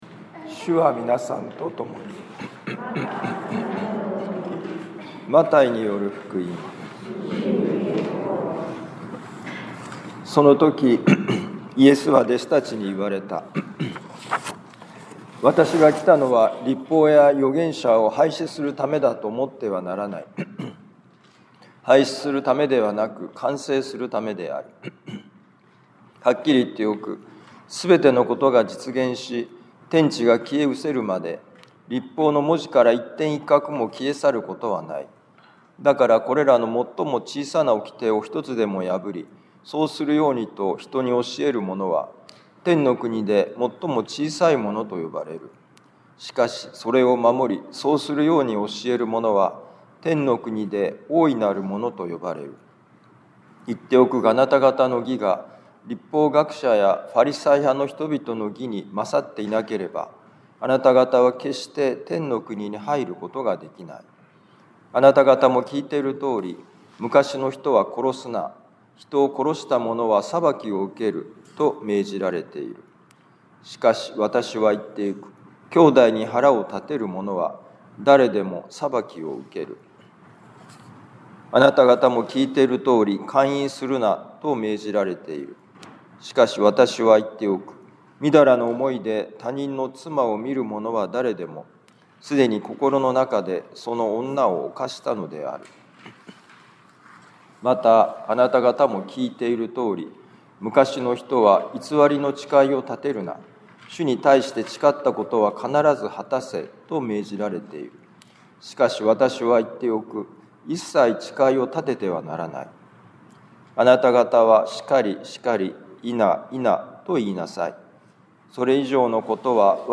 マタイ福音書5章17-37節「律法の完成とは」2026年2月15日年間第6主日防府カトリック教会